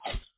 Can you add a sound when the snake eats food?
You’ll need to find a short MP3 sound effect and put it in your folder.
snake-eat.mp3